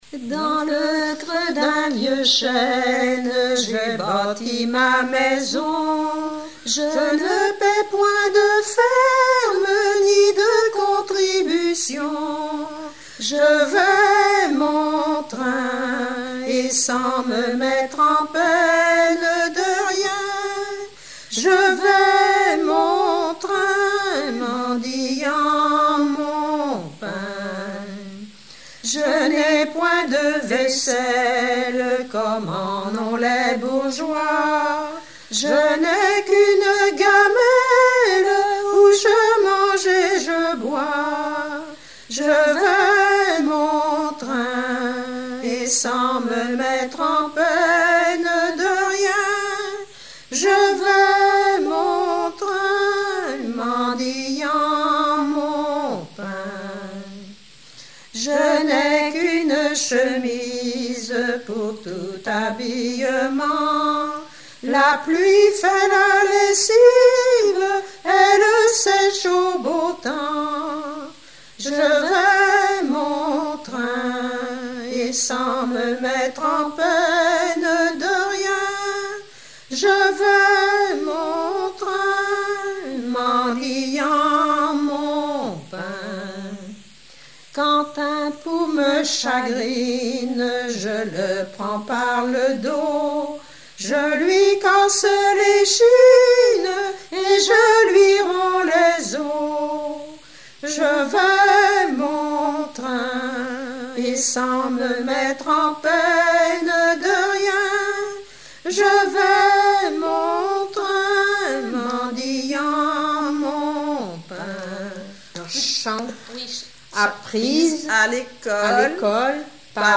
Mémoires et Patrimoines vivants - RaddO est une base de données d'archives iconographiques et sonores.
Genre strophique
Répertoire de chansons populaires et traditionnelles
Pièce musicale inédite